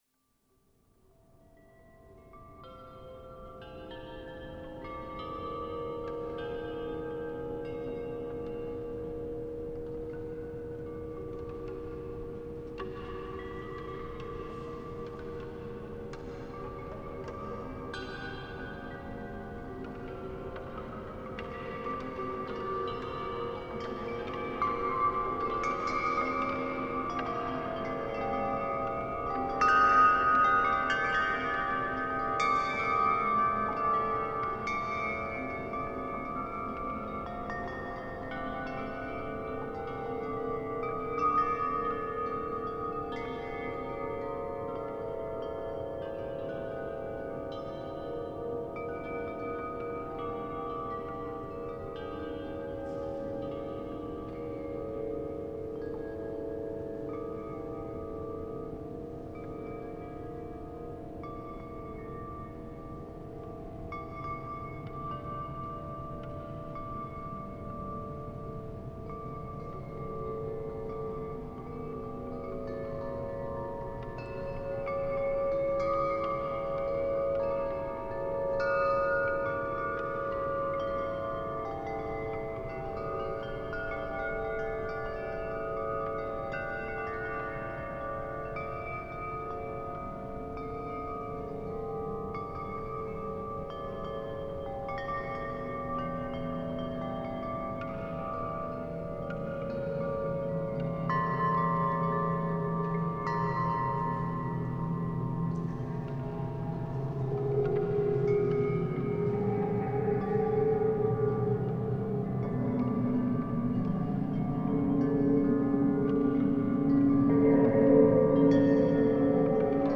Transformed wind chimes: Nature-Symphony 78 Sound Effect — Free Download | Funny Sound Effects